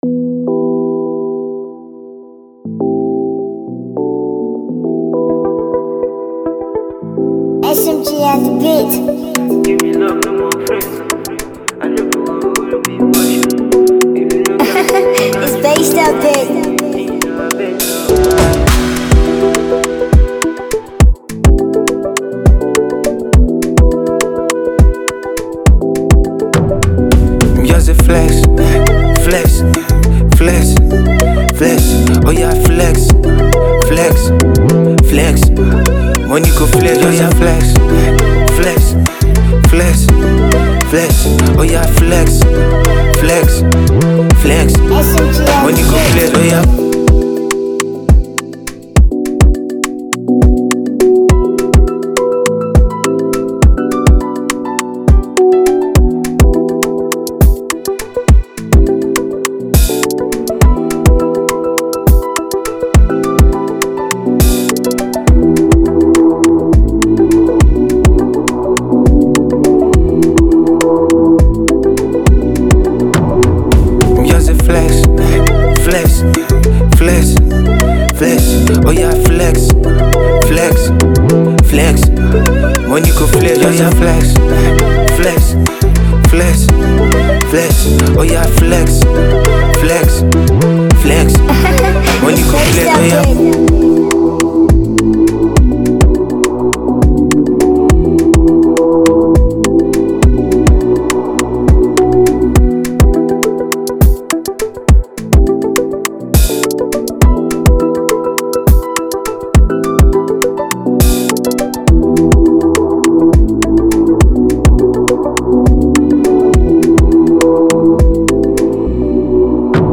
instrumental Free beat